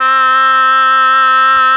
RED.OBOE  18.wav